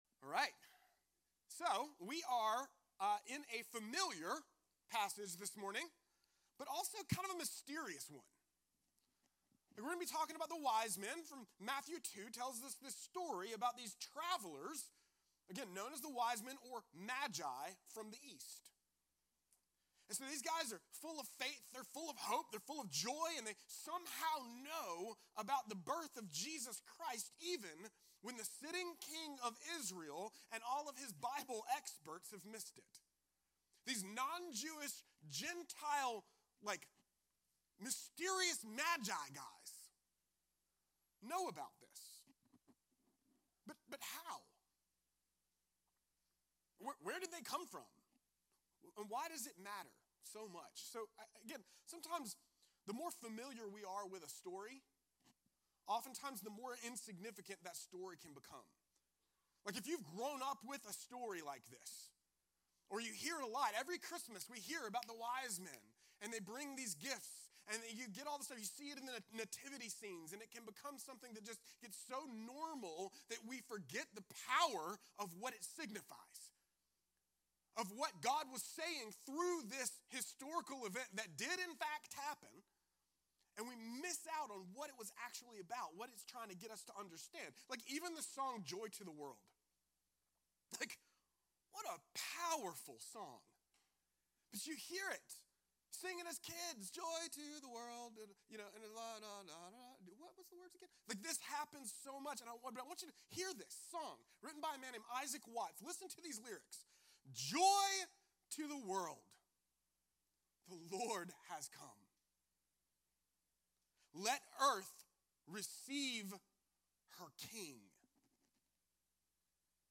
A message from the series "Unto Us."